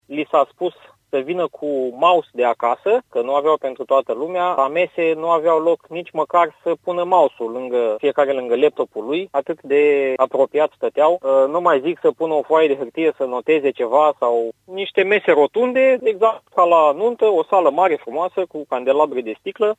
Părinții olimpicilor sunt nemulțumiți de condițiile în care au loc probele, mai ales că li s-ar fi transmis să aducă de acasă componente periferice pentru a putea susține examenul, a declarat pentru postul nostru de radio tatăl unui elev: ”Li s-a spus să vină cu mouse de acasă, că nu aveau pentru toată lumea.